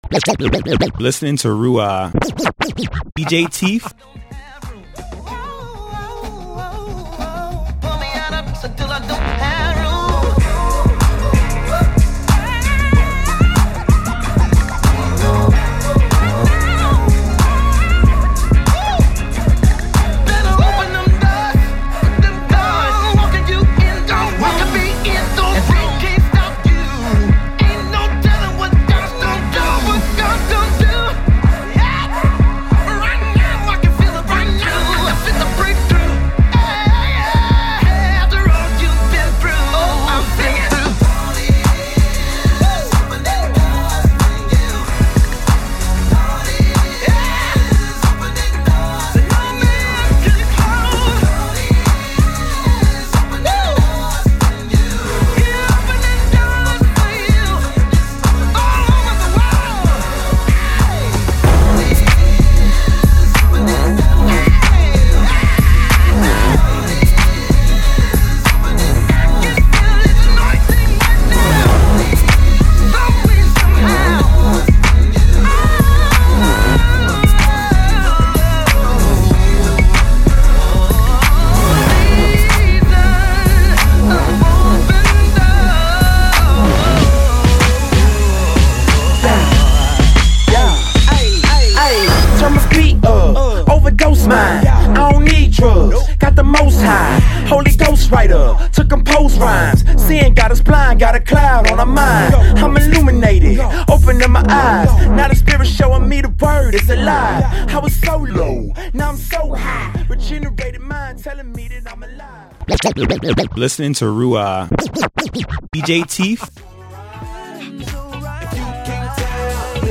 Urban-Adult Contemporary Mix Demo #2